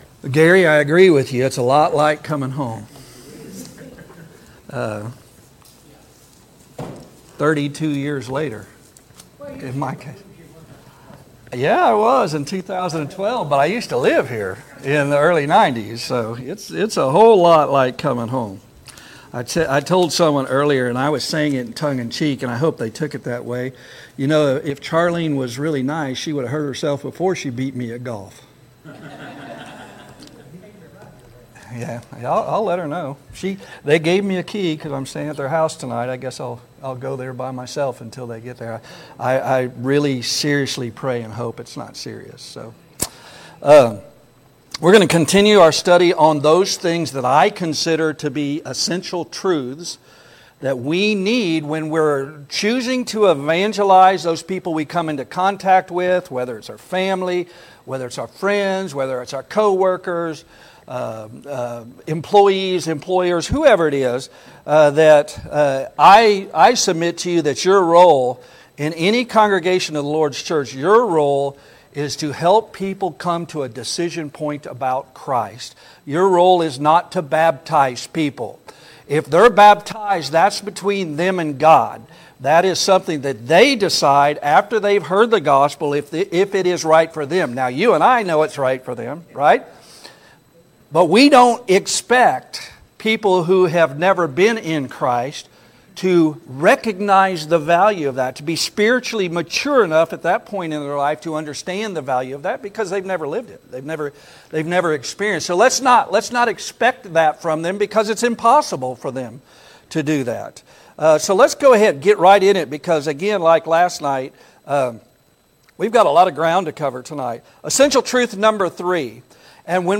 2026 Spring Gospel Meeting "Think Souls first" Passage: John 8:32; Romans 1:18-22 Service Type: Gospel Meeting « 4.